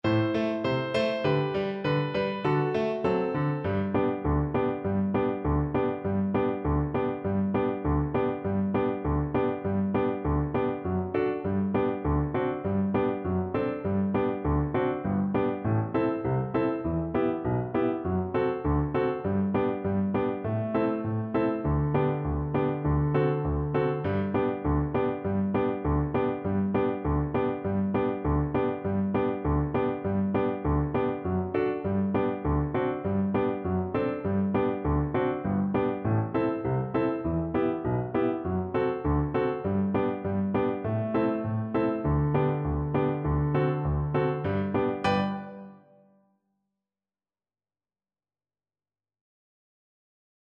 Allegretto
2/4 (View more 2/4 Music)
D6-D7